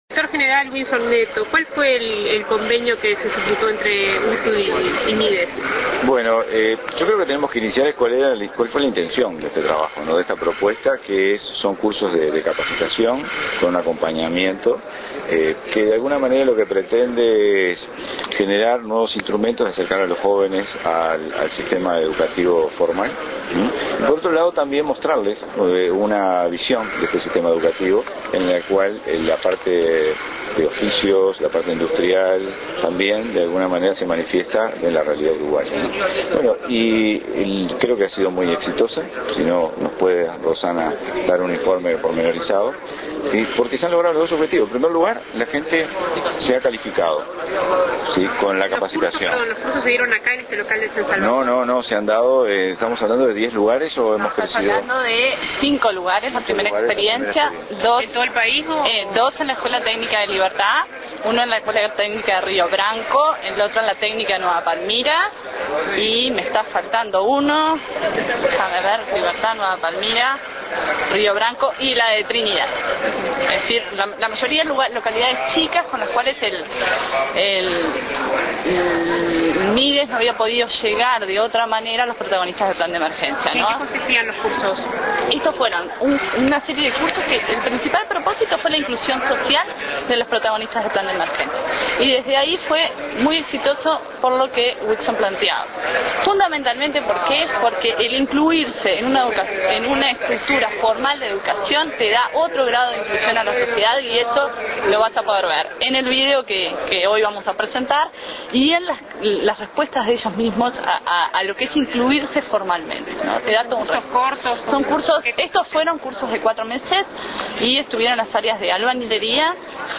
Declaraciones del Director General de UTU
en el cierre de proyectos MIDES-UTU.